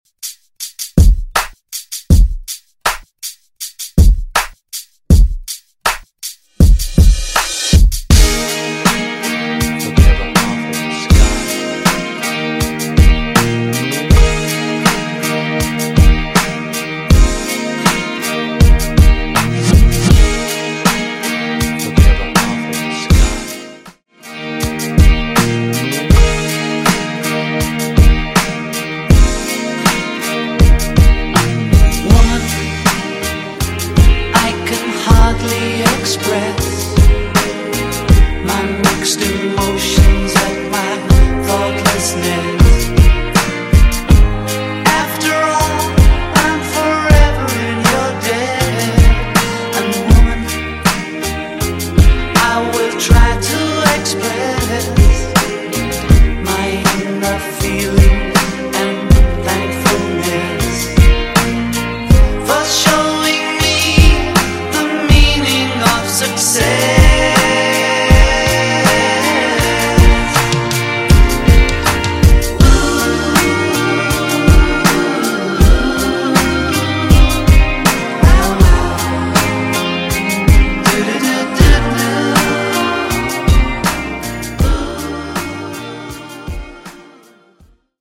Genre: 80's
Semi-Clean BPM: 122 Time